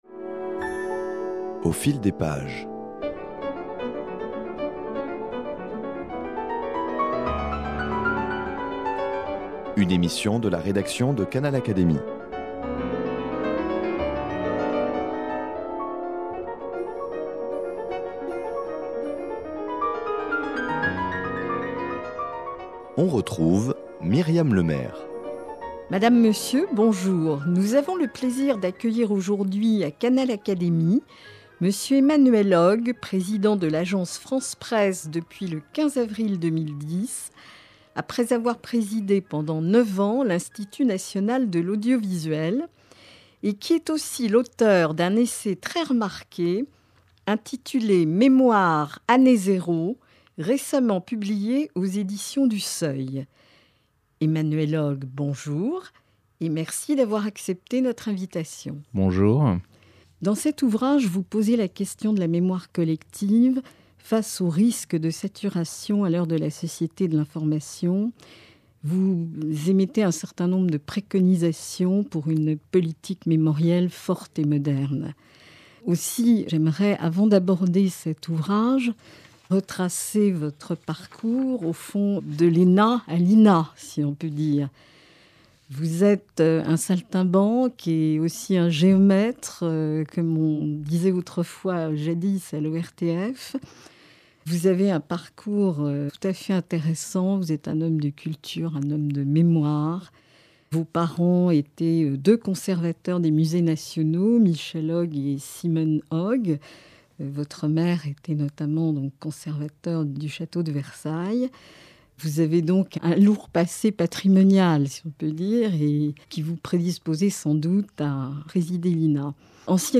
Quelques jours après l'enregistrement de cet entretien, il a été élu Président de l'Agence France Presse, AFP, le 15 avril 2010.